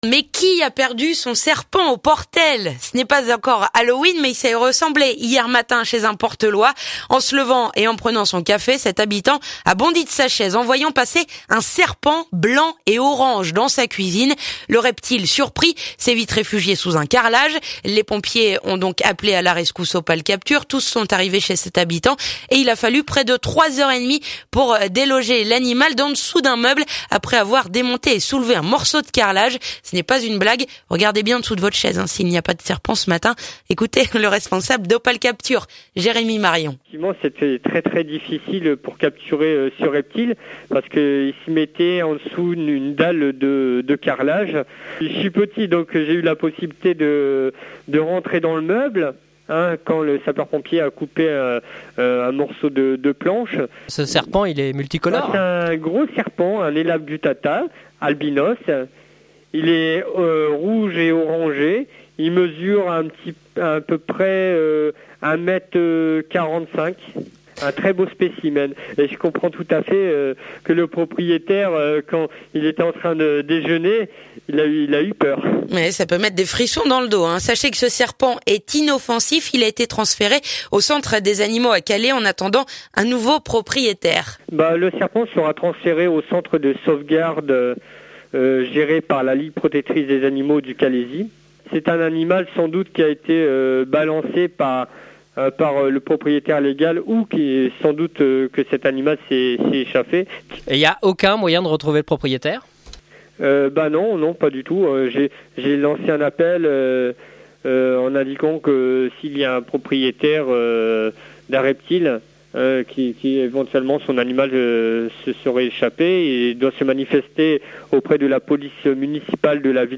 reportage !